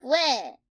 AV_deer_short.ogg